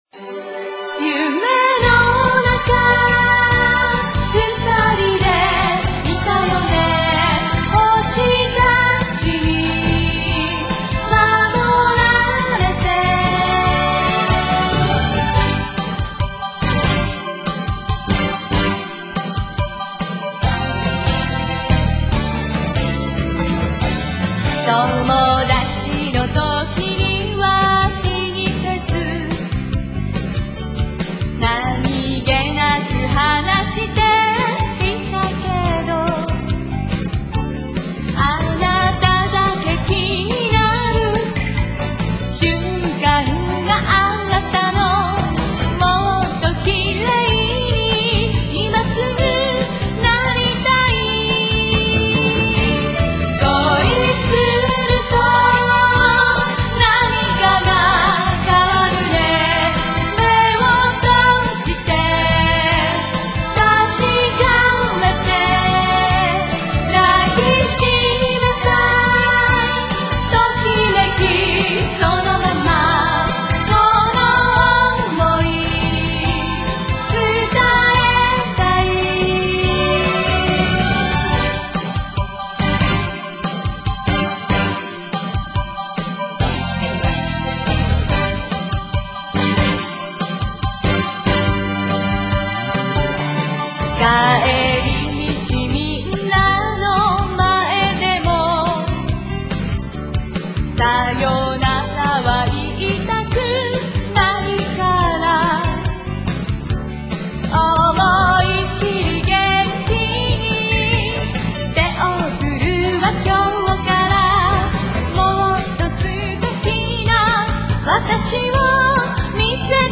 (со словами)